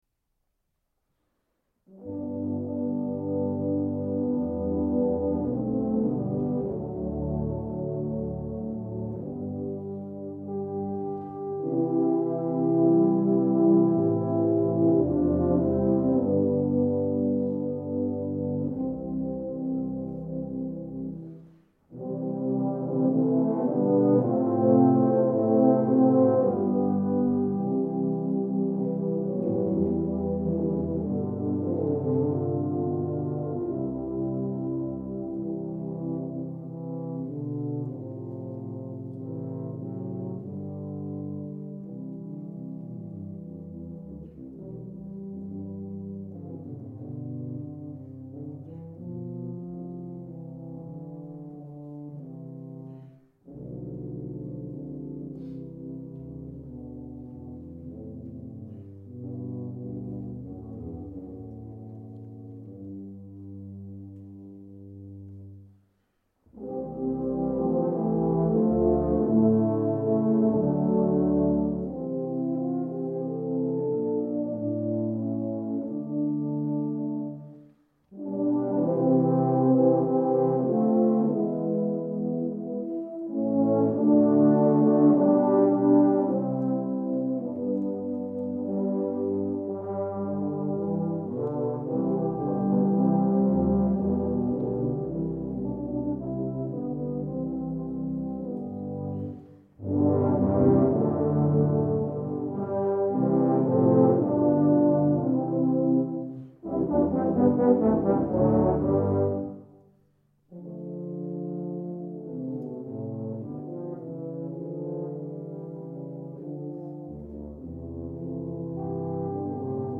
For Tuba Ensemble
2 Euphs. And 3 Tubas.